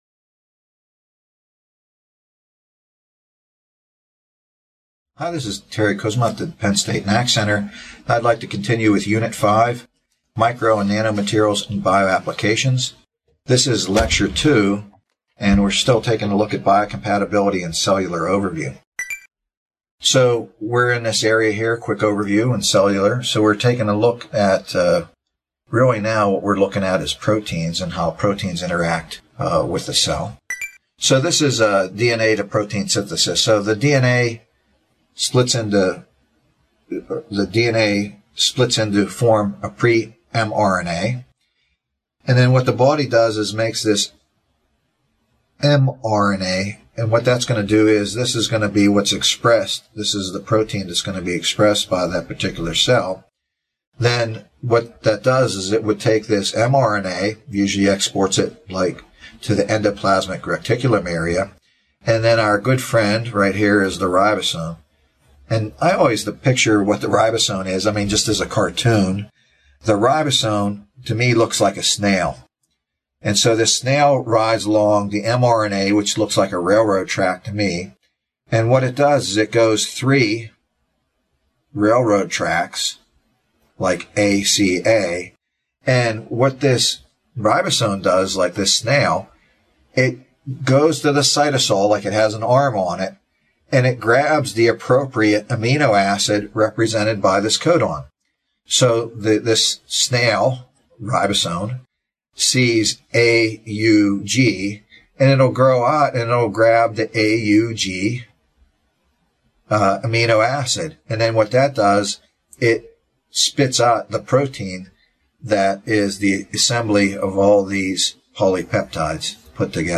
This video, provided by the Nanotechnology Applications and Career Knowledge Support (NACK) Center at Pennsylvania State University, is part two of a four-part lecture on the interactions between biological entities and products crafted on a nanoscale, with a focus on nanoparticles for drug delivery.